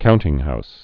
(kountĭng-hous)